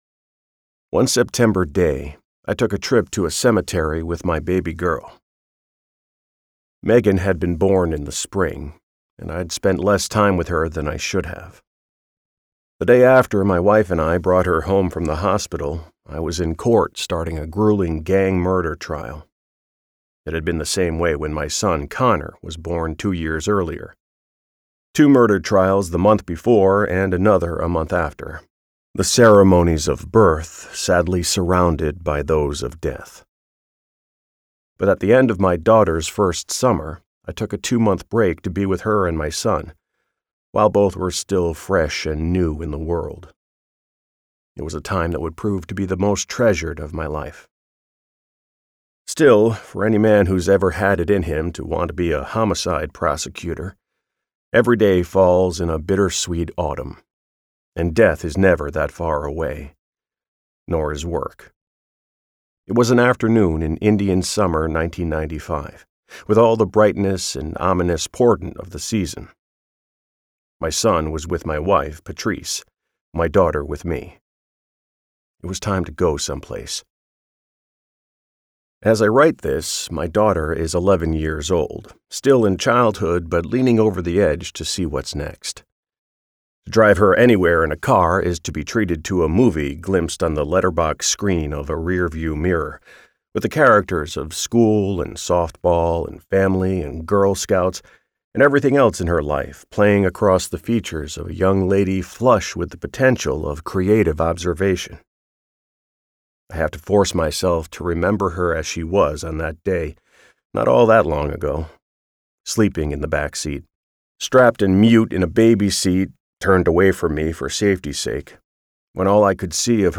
Relentless Pursuit - Vibrance Press Audiobooks - Vibrance Press Audiobooks